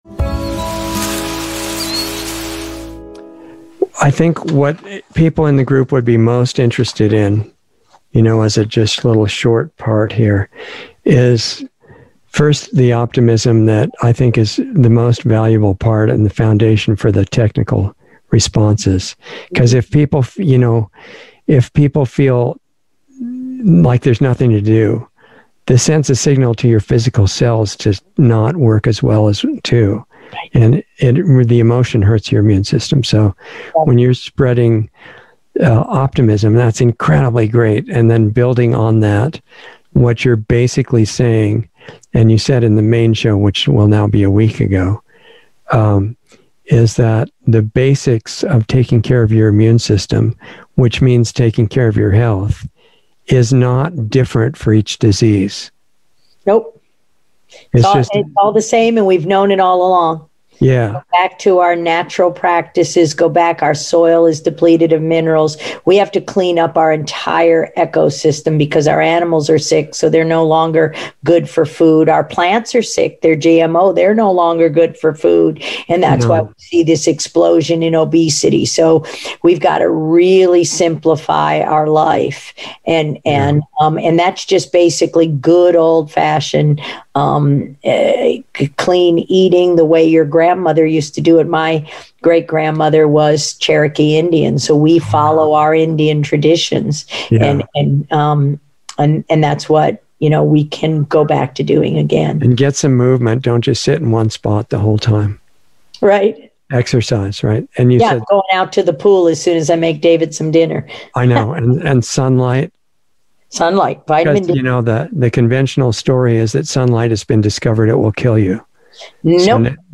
Planetary Healing Club - Dr. Judy Mikovits - Insider Interview 8/3/21